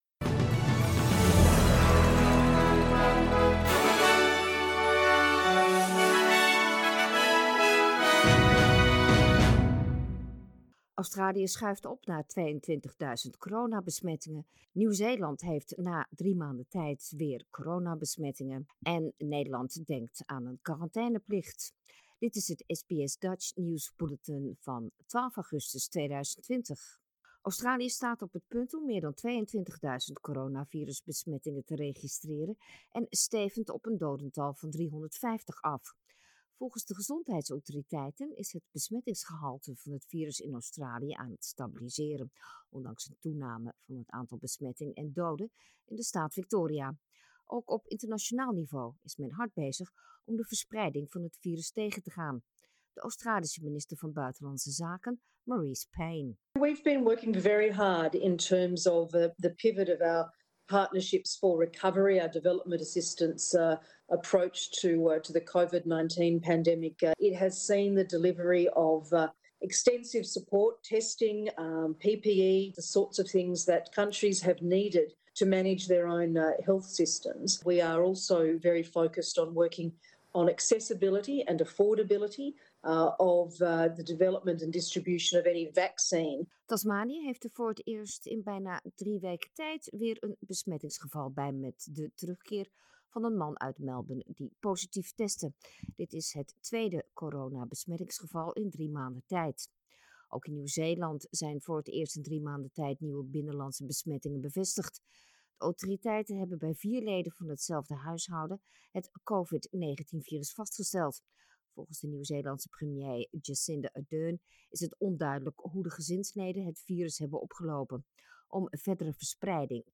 Nederlands/Australisch SBS Dutch nieuwsbulletin woensdag 12 augustus